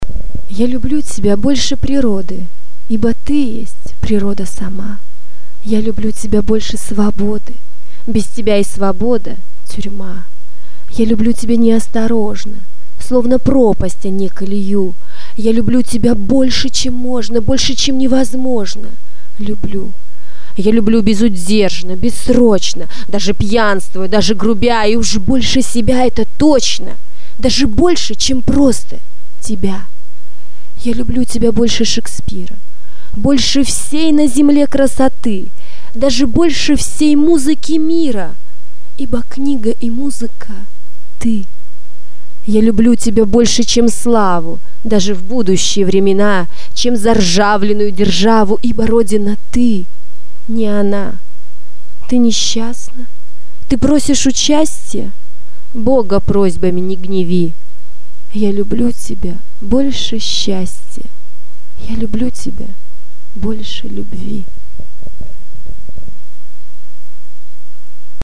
devushka-chitaet-stih-evgeniya-evtushenko-ya-lyublyu-tebya-bolshe-prirody